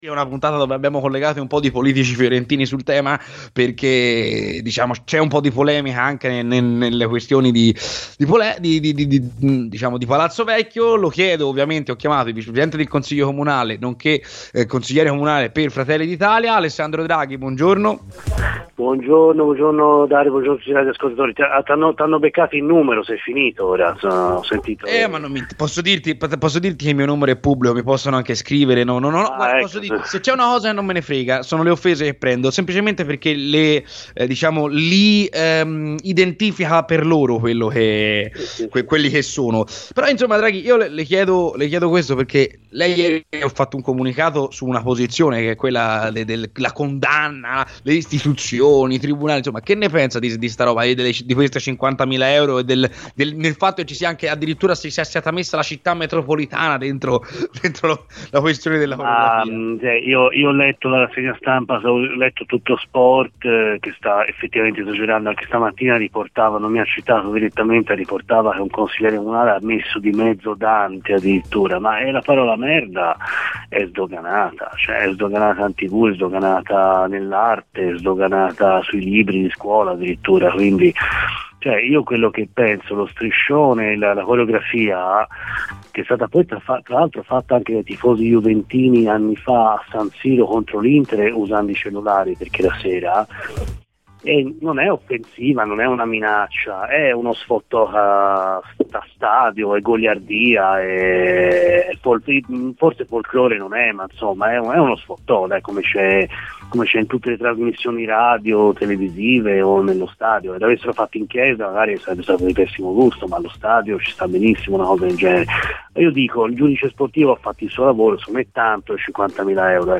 Il Vice Presidente del Consiglio Comunale Alessandro Draghi è intervenuto ai microfoni di Radio FirenzeViola durante la trasmissione "C'è Polemica".